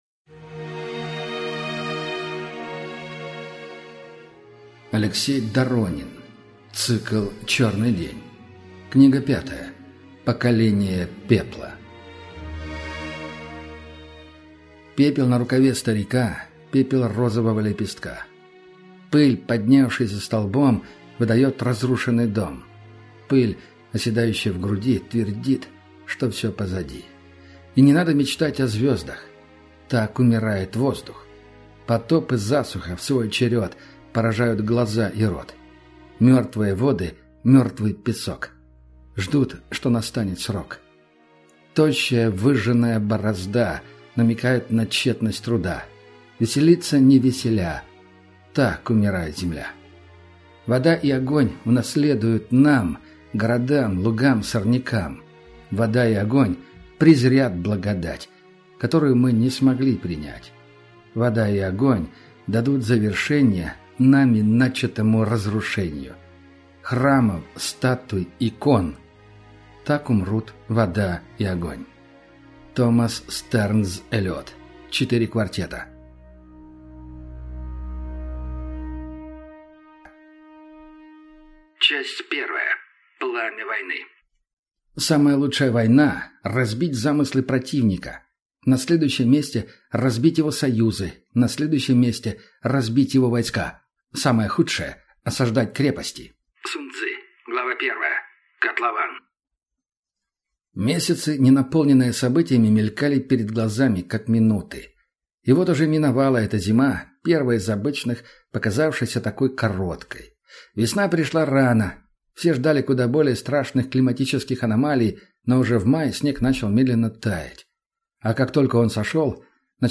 Аудиокнига Поколение пепла | Библиотека аудиокниг